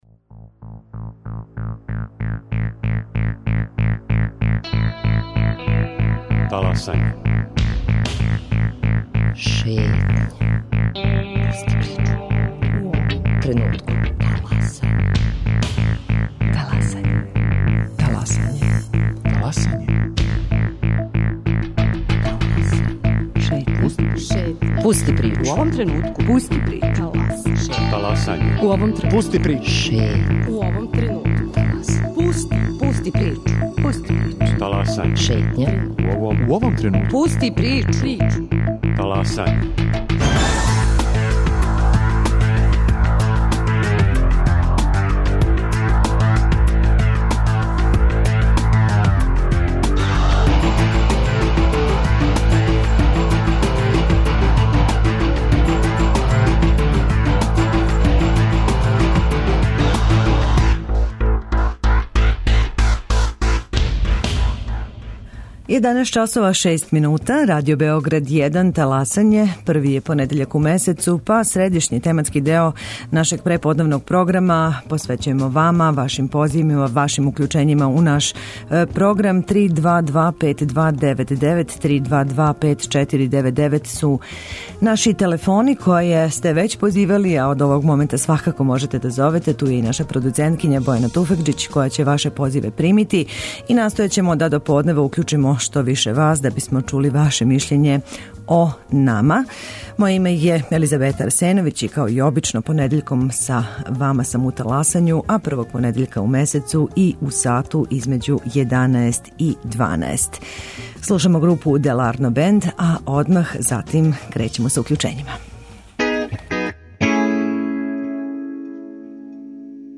Контакт програм Радио Београда 1!